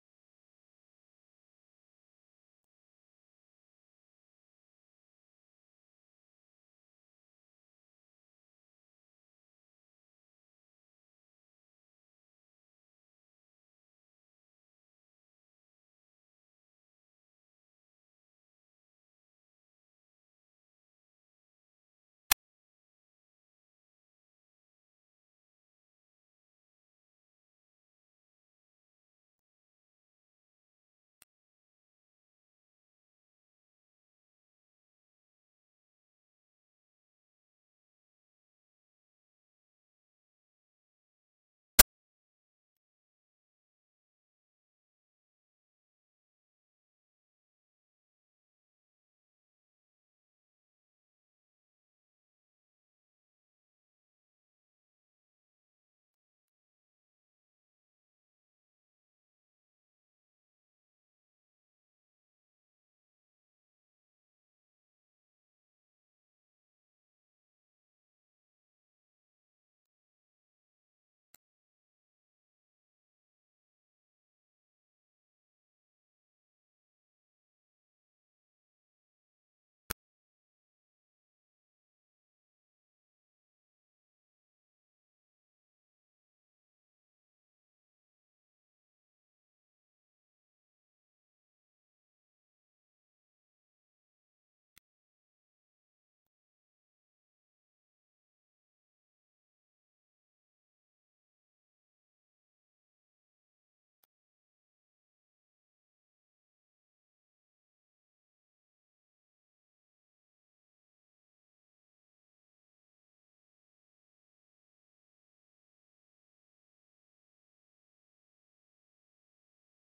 country - guitare - bluesy - cosy - harmonica